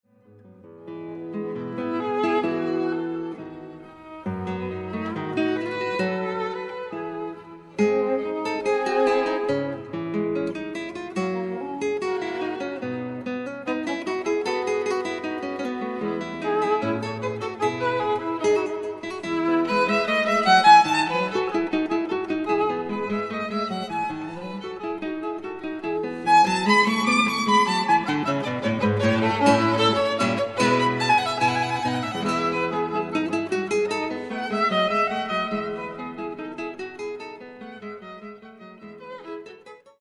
小提琴
吉他
【所屬類別】 XRCD唱片　　古典音樂